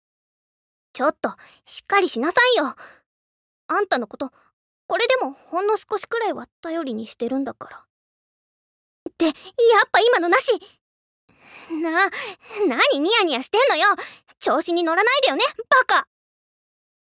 ボイスサンプル
ツンデレ